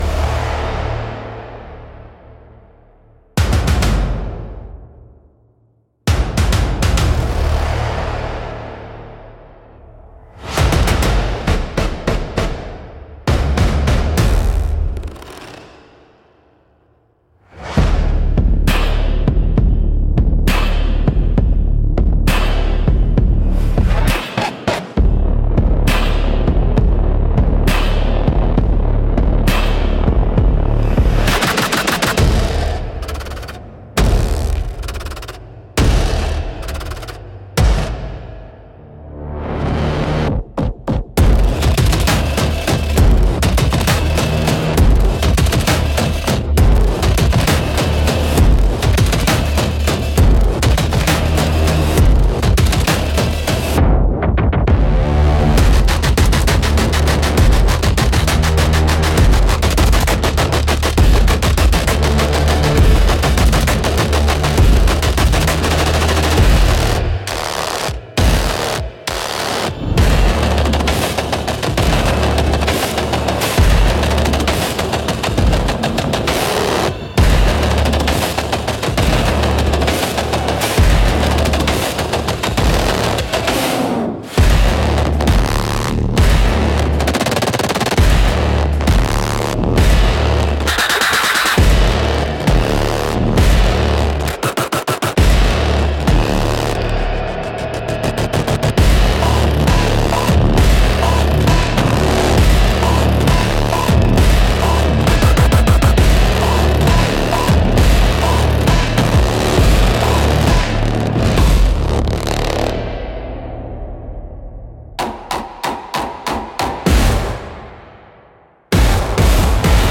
Instrumental - Dawn over Black Sand -3.24